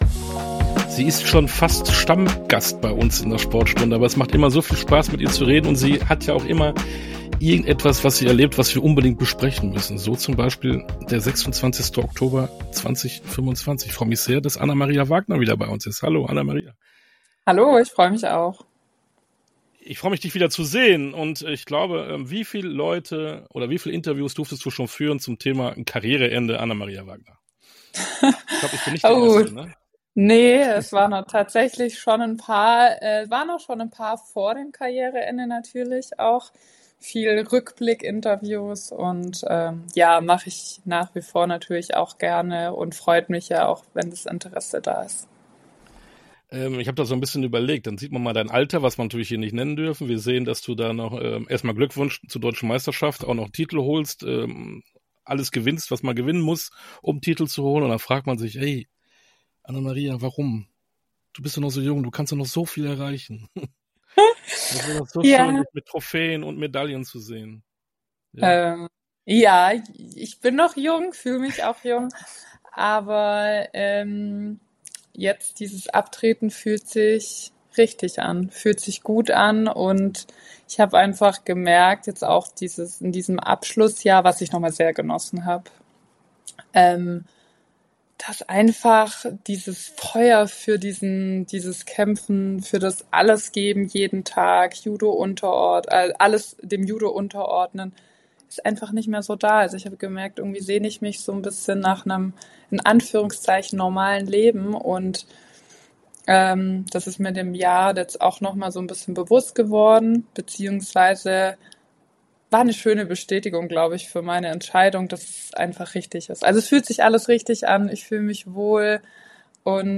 Sportstunde - Interview mit Anna-Maria Wagner - Judo Weltmeisterin ~ Sportstunde - Interviews in voller Länge Podcast
Interview_Anna-Maria_Wagner-_Judo_-_Weltmeisterin.mp3